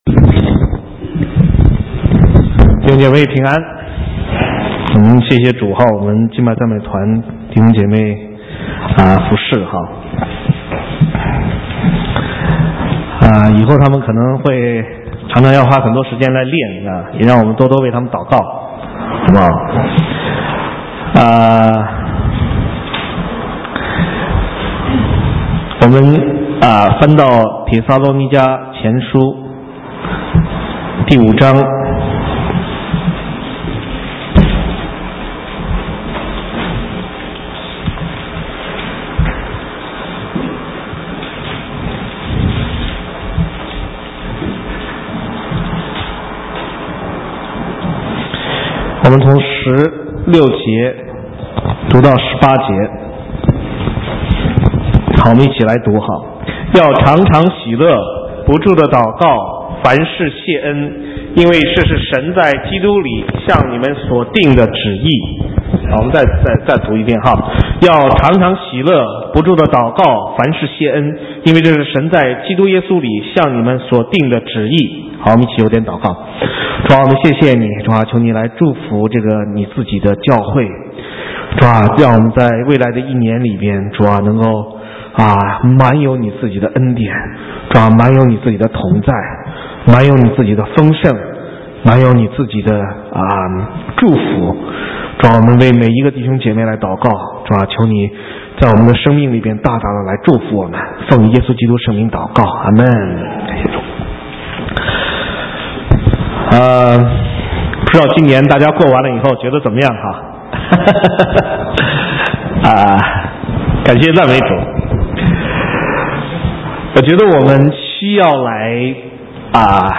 神州宣教--讲道录音 浏览：感恩的人生 (2010-11-28)